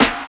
85 STREET SN.wav